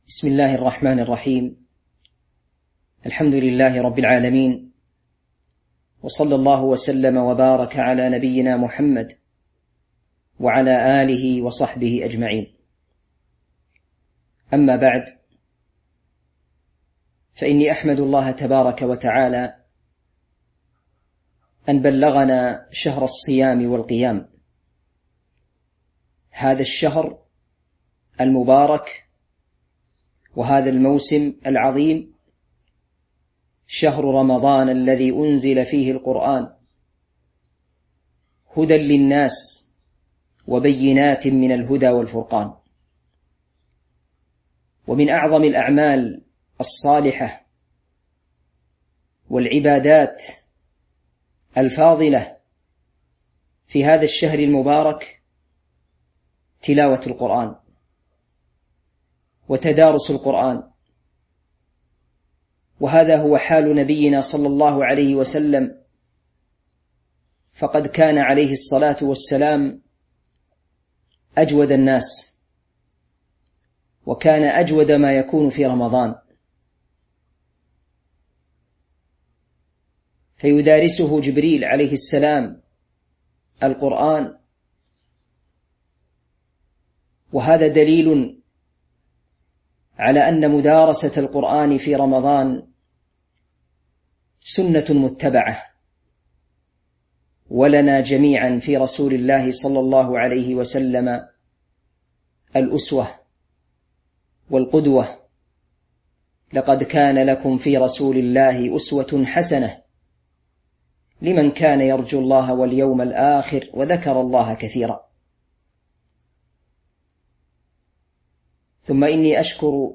تاريخ النشر ٩ رمضان ١٤٤٢ هـ المكان: المسجد النبوي الشيخ